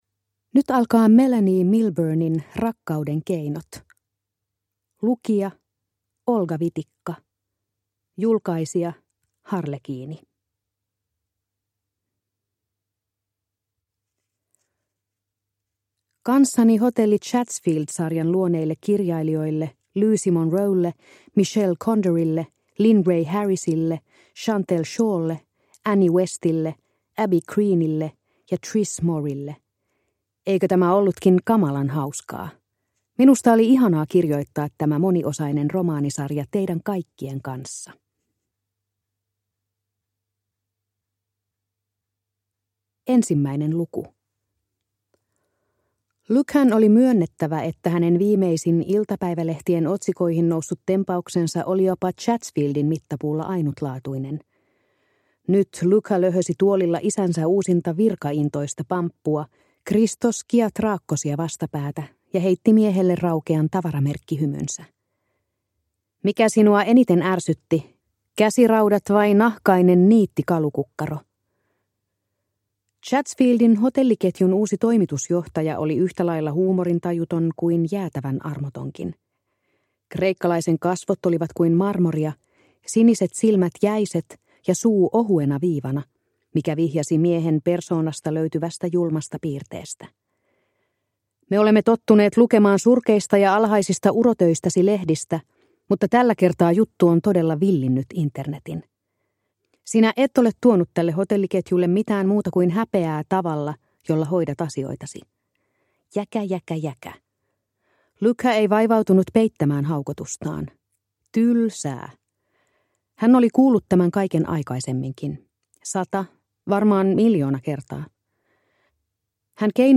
Rakkauden keinot – Ljudbok – Laddas ner